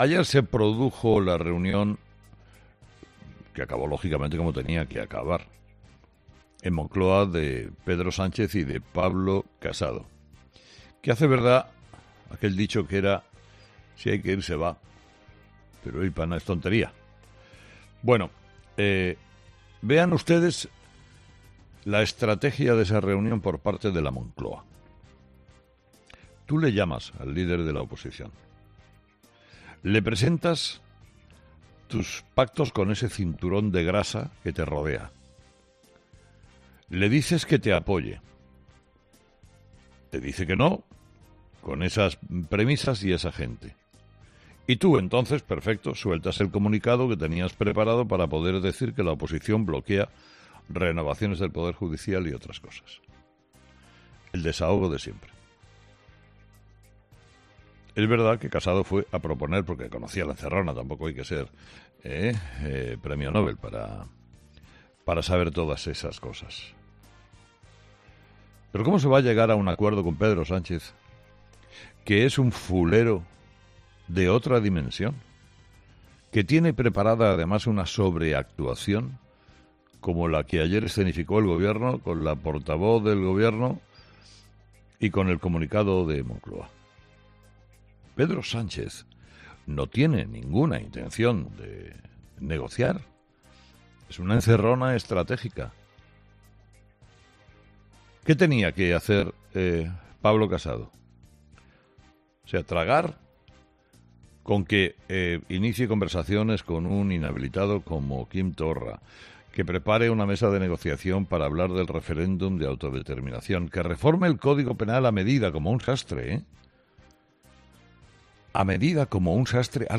Sobre esto quiso pronunciarse Carlos Herrera en su monólogo de las 06.00, y mandó 'un recado' a Sánchez por querer imponer 'su traje a medida', muy lejos de la moderación, a Pablo Casado: